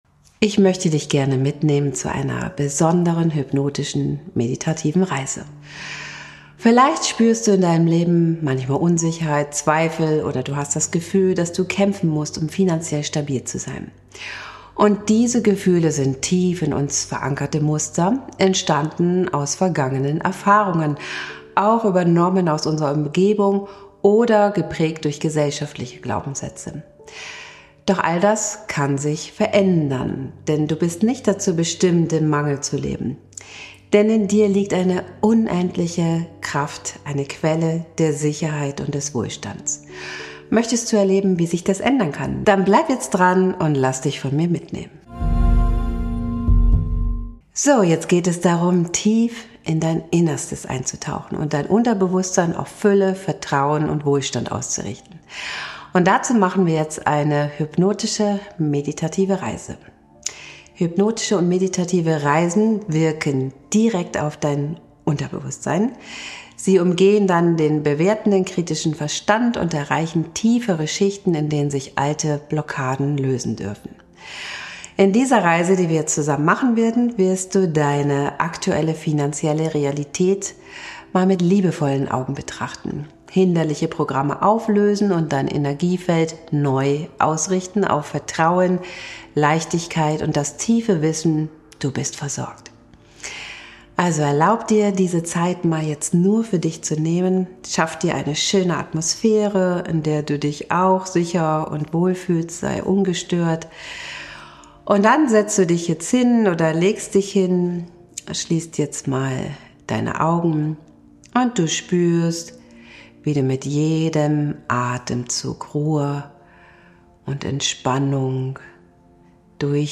Du wirst dein Unterbewusstsein neu ausrichten, dein Vertrauen in die finanzielle Fülle stärken und dich für einen natürlichen Geldfluss und Wohlstand öffnen. Lass dich in eine tiefe Entspannung führen und erfahre, wie du mit einer sanften, aber kraftvollen Hypnose dein energetisches Fundament für Fülle und Sicherheit erschaffen kannst!